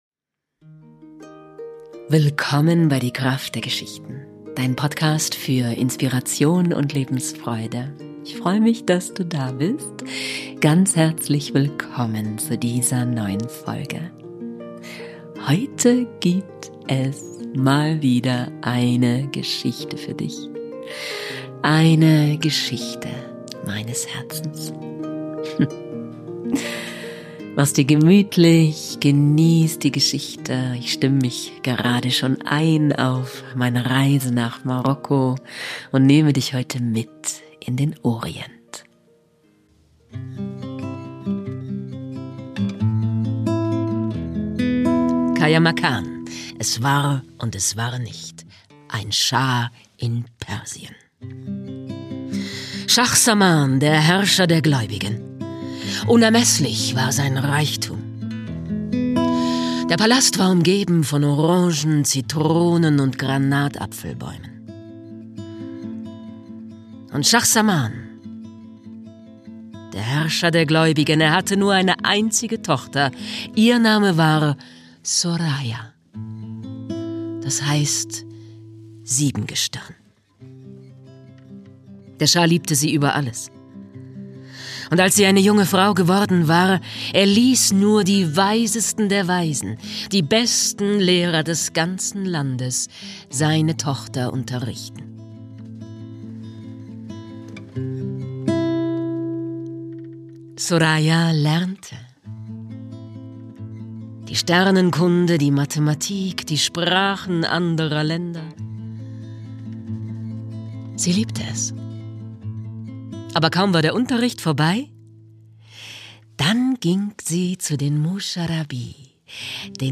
Während draußen die Schneeflocken fallen, erzähle ich Dir eine Geschichte.